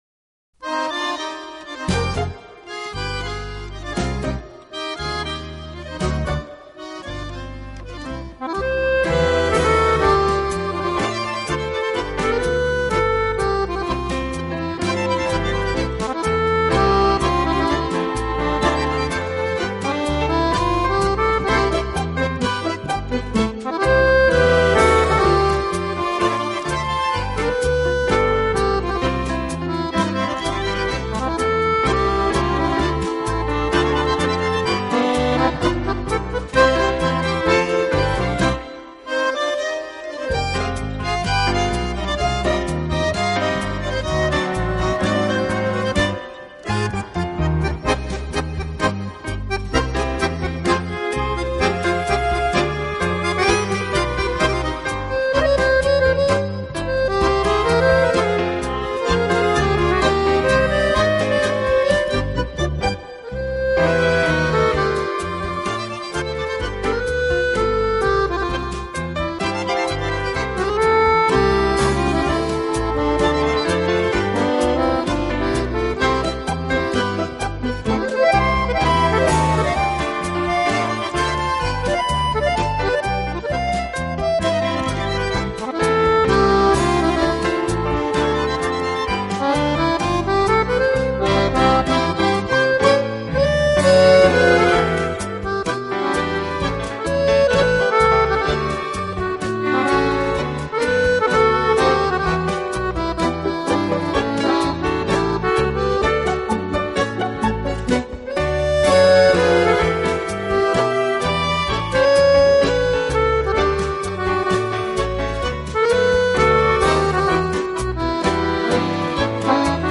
【手风琴】
40年代，他已蜚声国际，其清新、令人愉悦的演奏风格受到了很多人的喜爱。